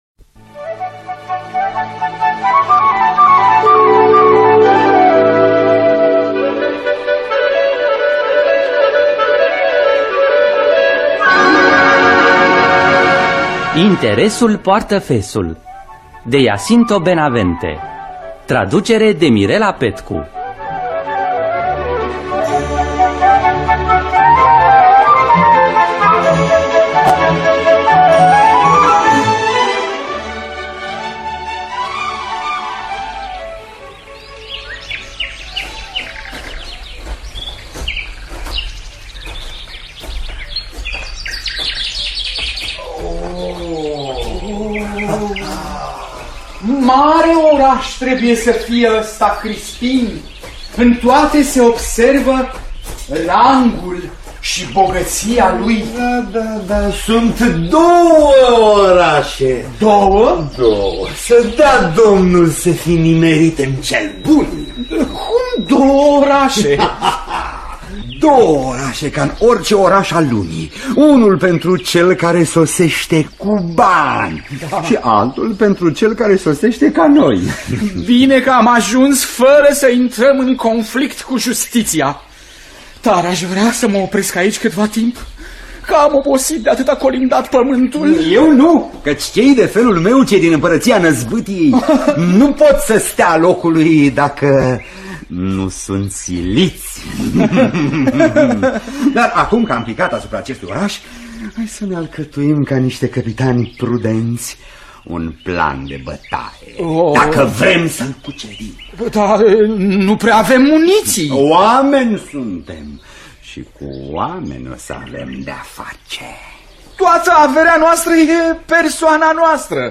“Interesul poartă fesul” de Jacinto Benavente – Teatru Radiofonic Online
Adaptarea radiofonică şi regia artistică